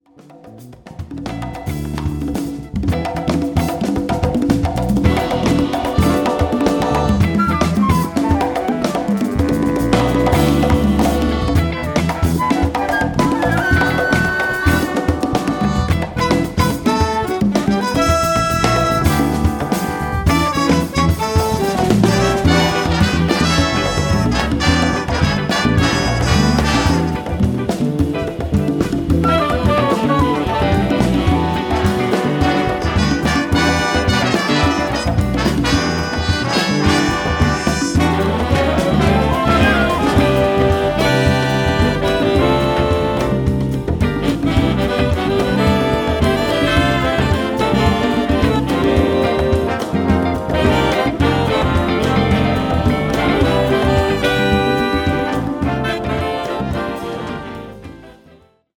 Suite für Bigband und Solisten